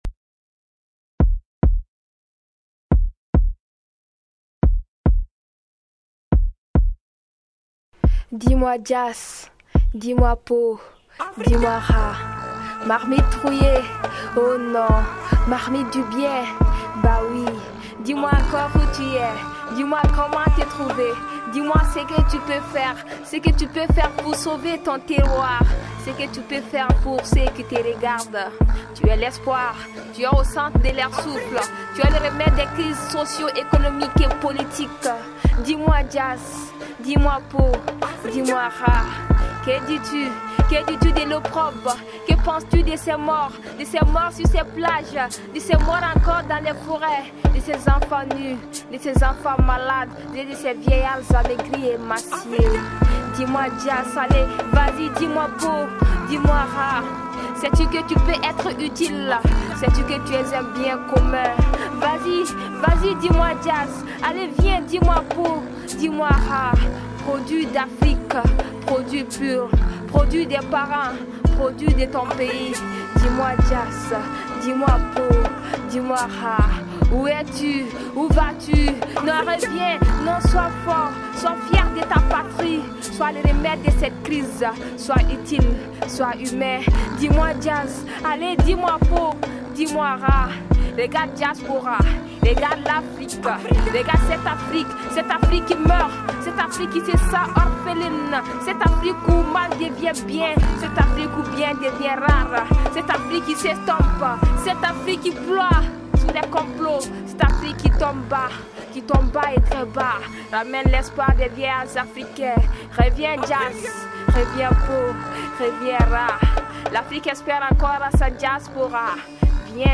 Elle s'addresse avec émotion à la diaspora.